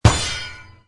shield-guard-6963.wav